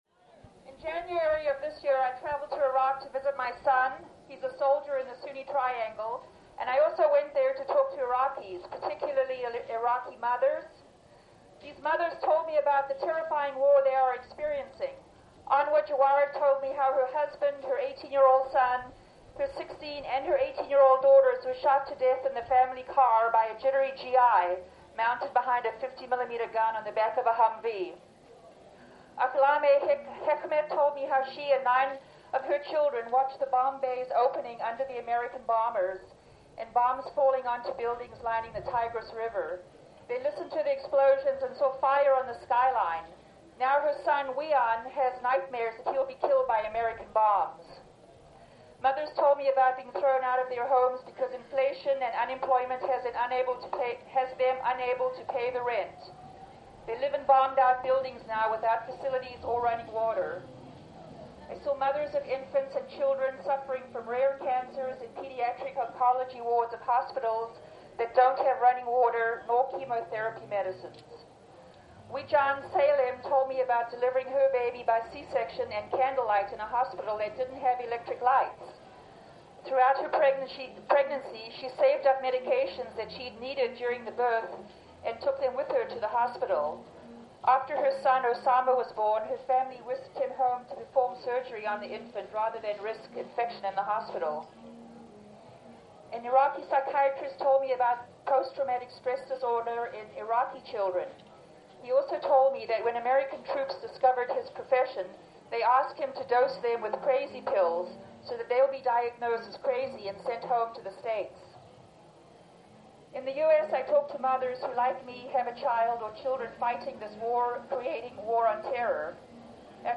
April 7, 2004: One year after Oakland Police attacked the first such direct action against war profiteers APL and SSA, antiwar protesters once again shutdown the SSA docks at the Port of Oakland... here are some audio clips.